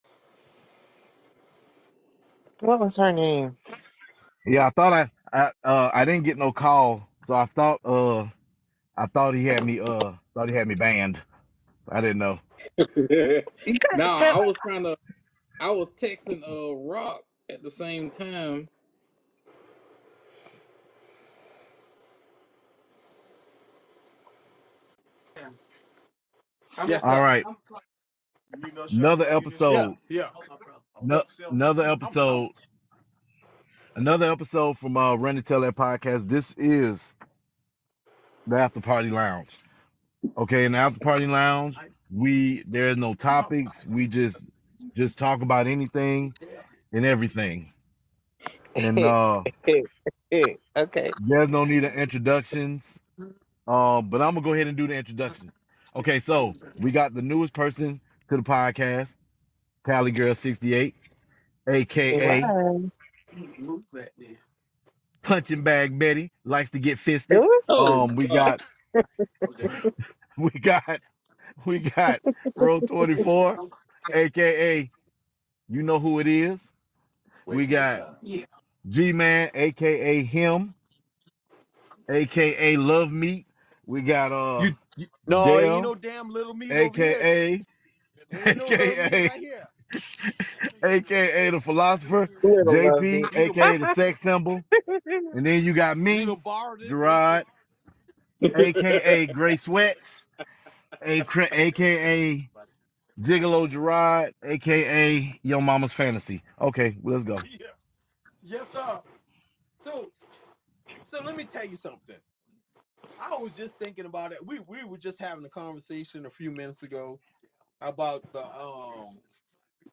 Conversation without topics